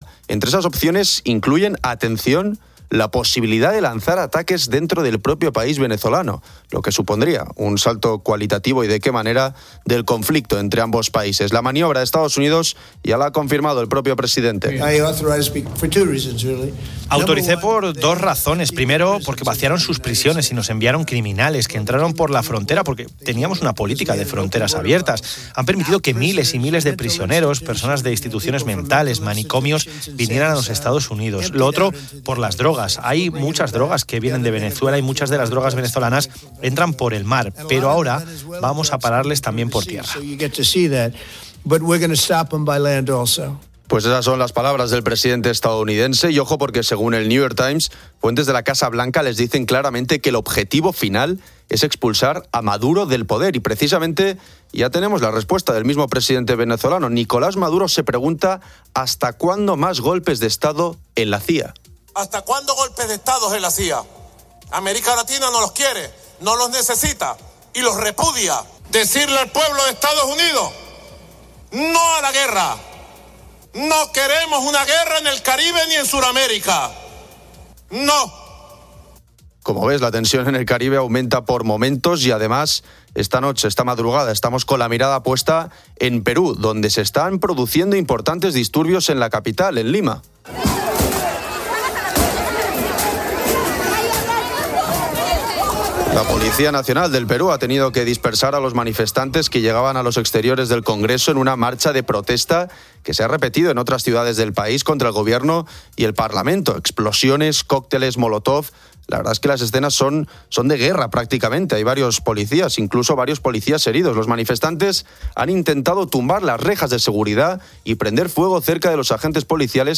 informa para COPE desde una base de la OTAN en Eslovaquia
Los oyentes de COPE comparten sus hábitos de consumo de series.